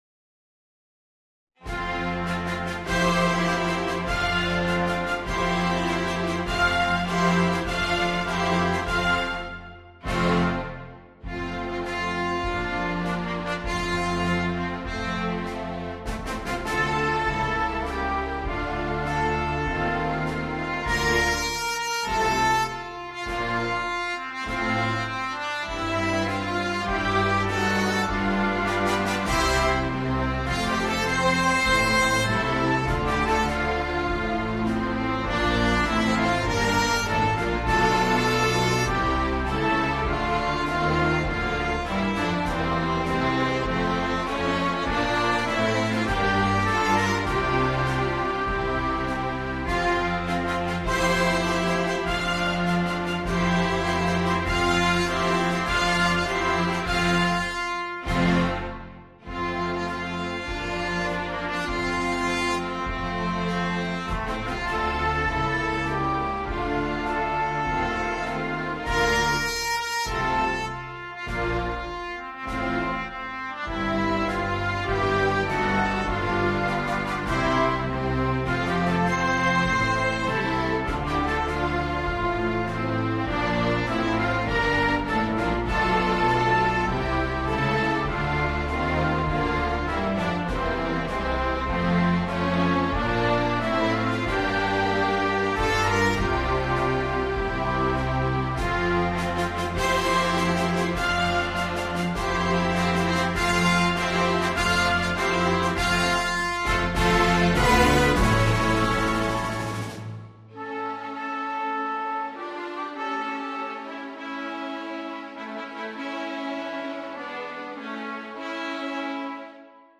The MP3 was recorded with NotePerformer 3.
Patriotic